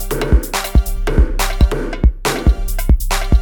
beat beats drumkit fast Gabber hardcore House Jungle sound effect free sound royalty free Music